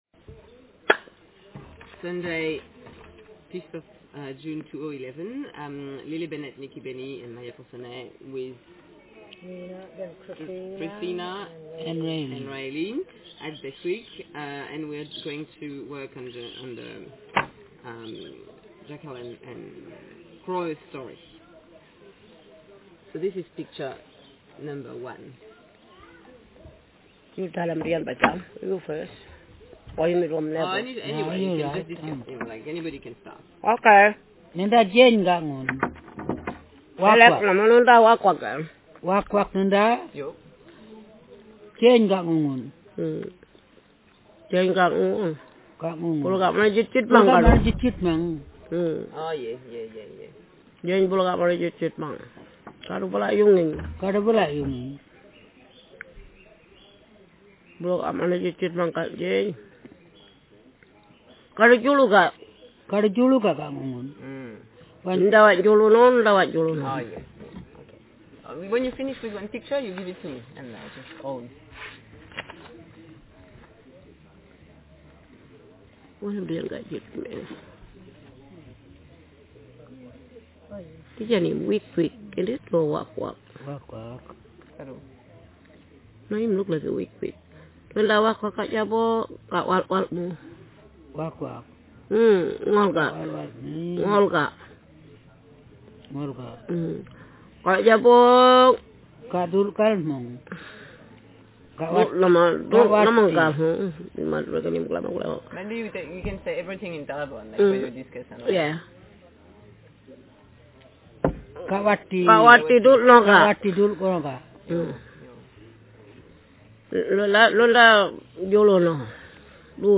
Speaker sex f/f Text genre stimulus retelling